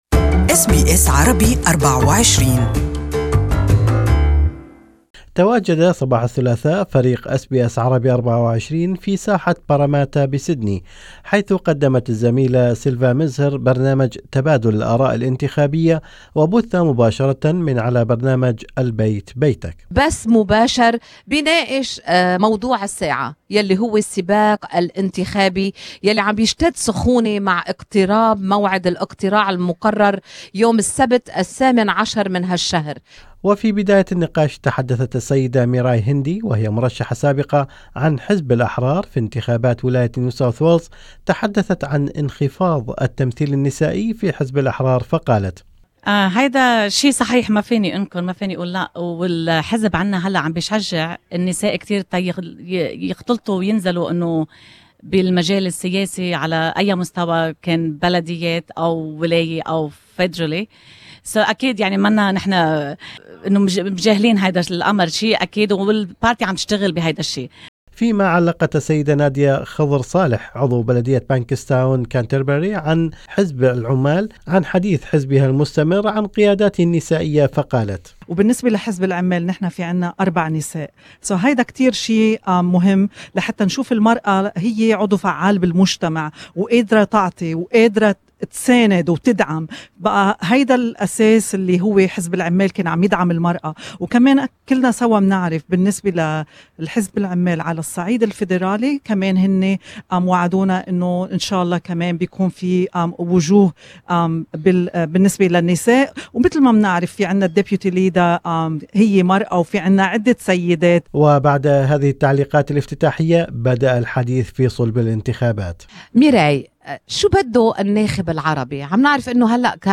SBS Arabic24's Elections Exchange from Paramatta
An audio summary of SBS Radio elections exchange in Arabic is available in this feature.